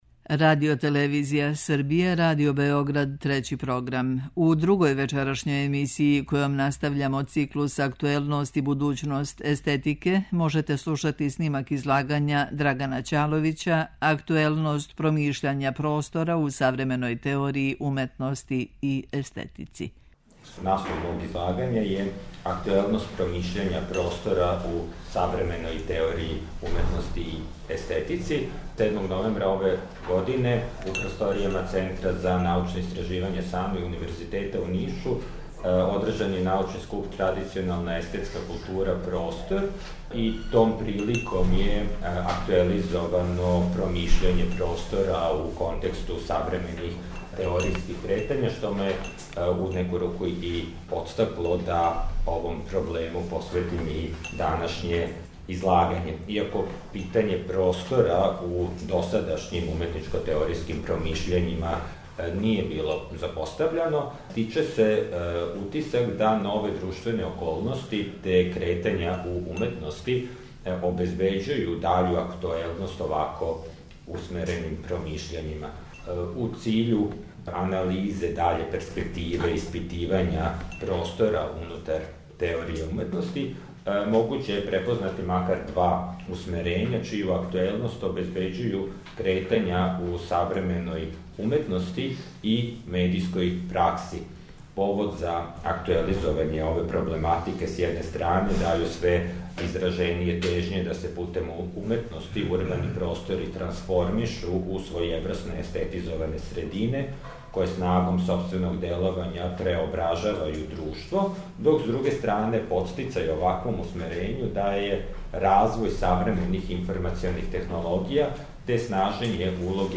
Научни скупови
У Заводу за проучавање културног развитка у Београду одржан је дводневни мултидисциплинарни научни скуп Естетичког друштва Србије о теми Актуелност и будућност естетике.